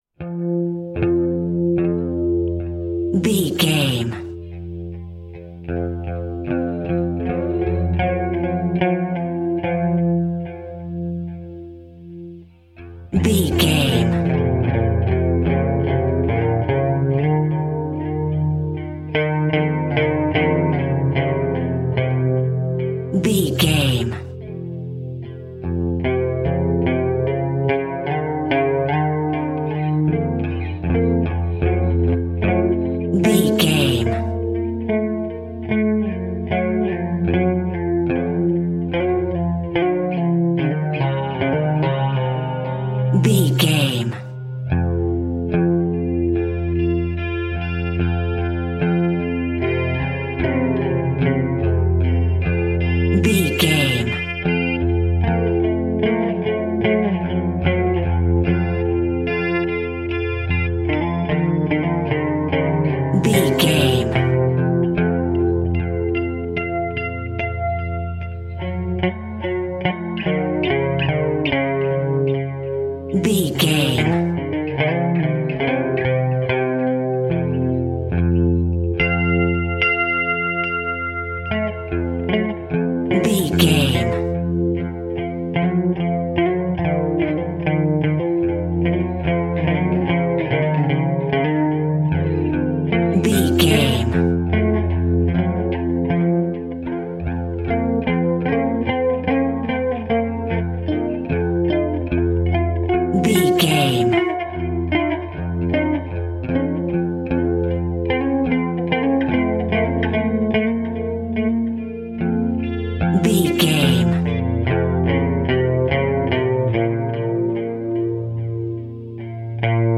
Phrygian
Slow
scary
ominous
dark
suspense
eerie
electric guitar
synth
ambience
pads